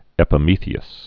(ĕpə-mēthē-əs, -thys)